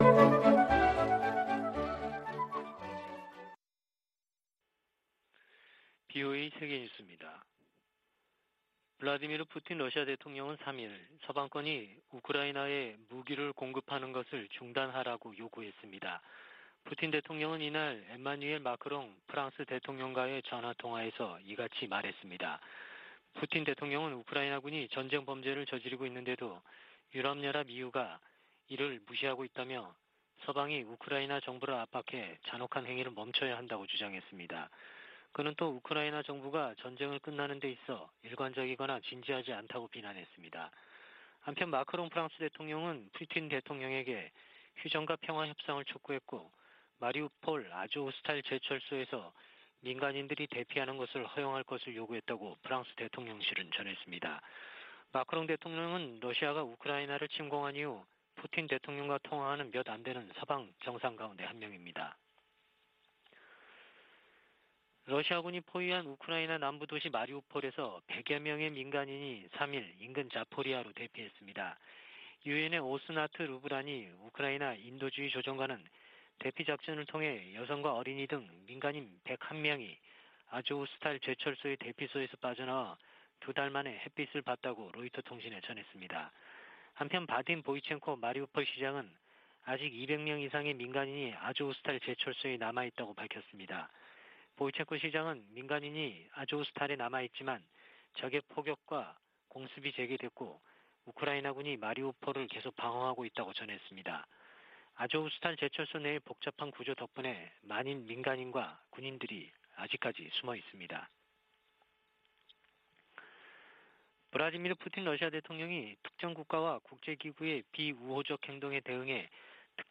VOA 한국어 아침 뉴스 프로그램 '워싱턴 뉴스 광장' 2022년 5월 4일 방송입니다. 미 국무부는 북한 풍계리 핵실험장 복구 조짐에 대해 위험한 무기 프로그램에 대처할 것이라고 밝혔습니다. 한국의 대통령직 인수위원회는 북한 비핵화 추진을 국정과제로 명시했습니다. 한국과 중국의 북 핵 수석대표들은 북한의 핵실험 재개 움직임 등에 대해 상황 악화를 막기 위해 공동으로 노력하기로 다짐했습니다.